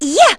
Laudia-Vox_Attack1.wav